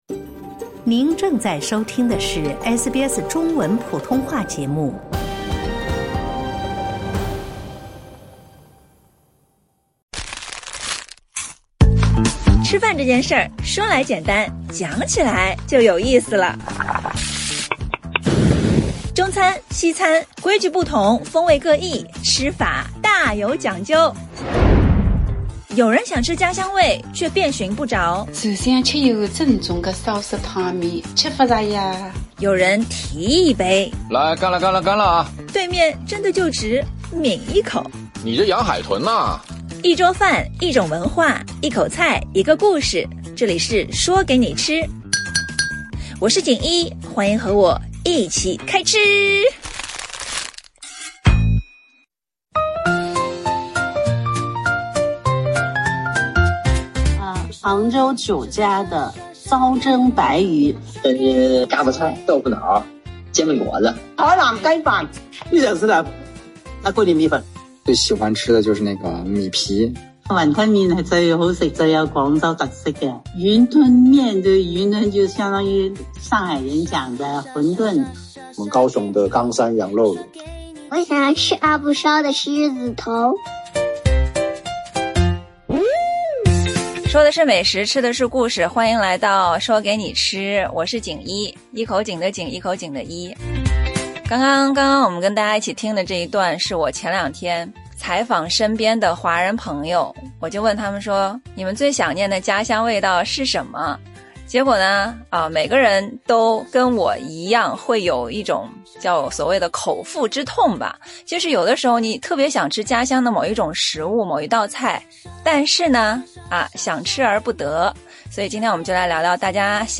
多年经验的主厨